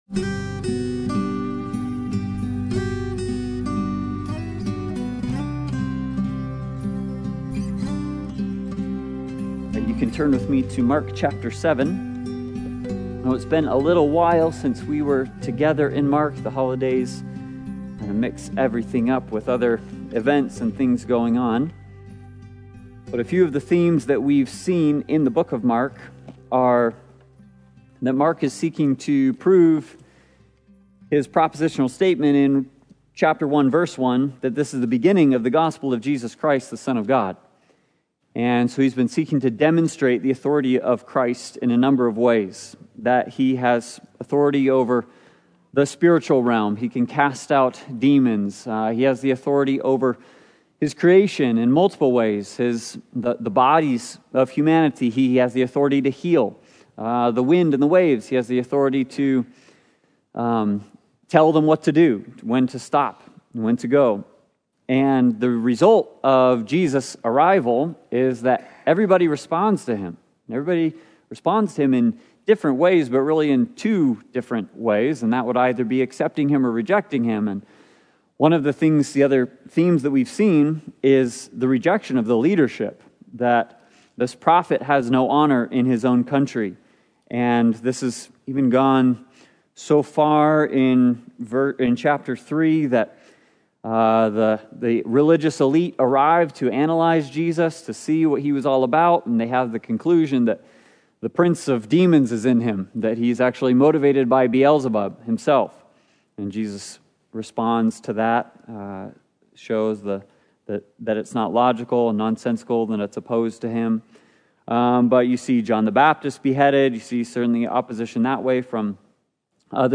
Passage: Proverbs 6:20-35 Service Type: Sunday Bible Study